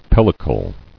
[pel·li·cle]